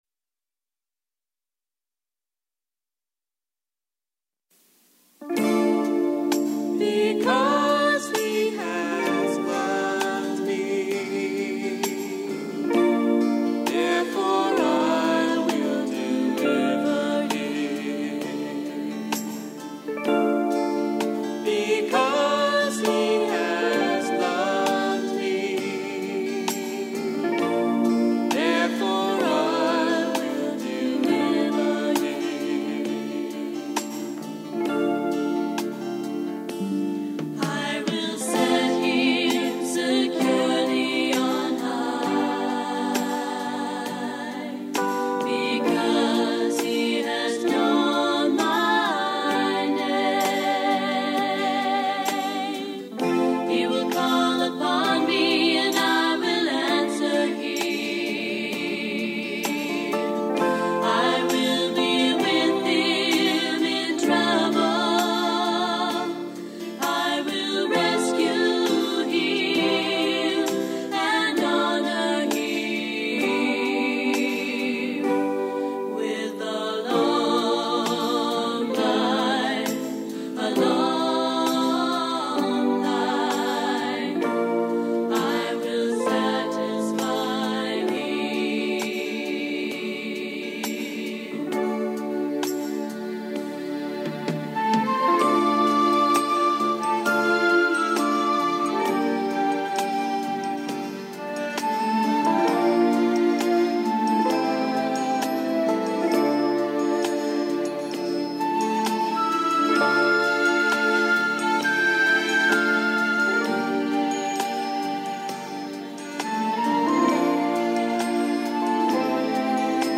Psalm 91 -a musical rendition
Psalm 91- Song